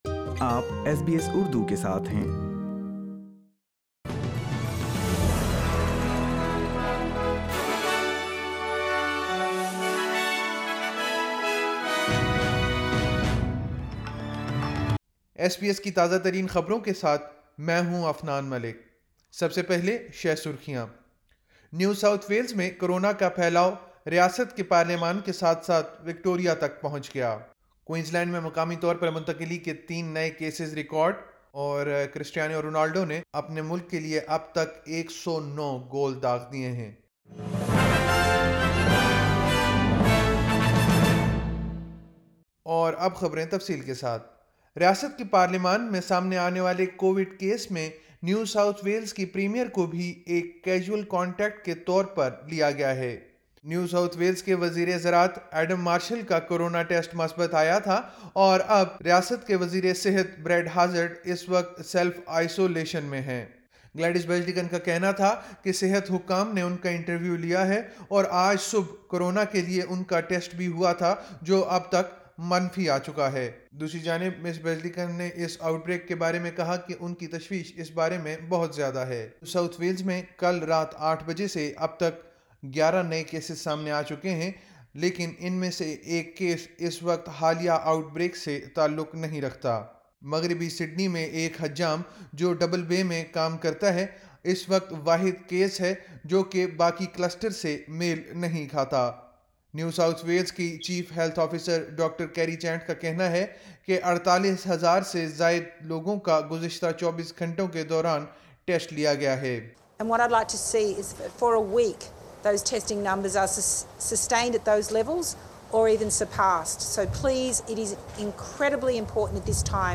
SBS Urdu News 24 June 2021